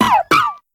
Catégorie:Cri Pokémon (Soleil et Lune) Catégorie:Cri de Rocabot